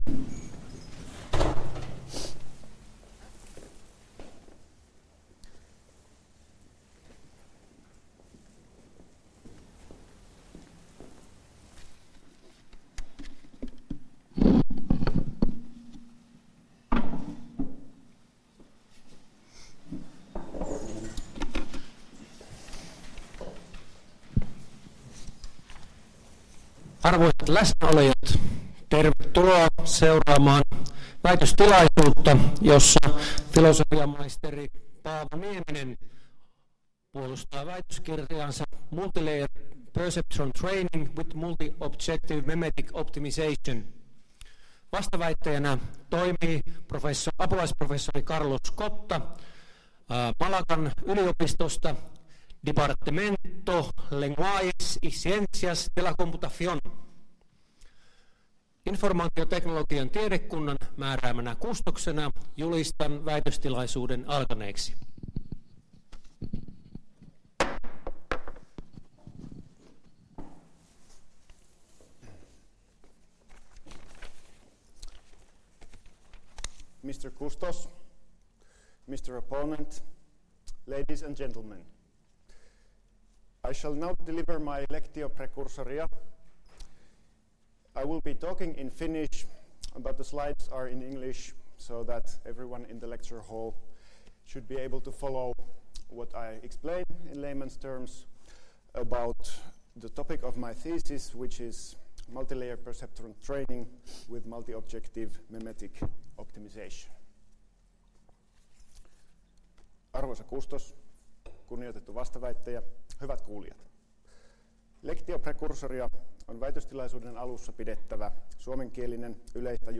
Väitöstilaisuus — Moniviestin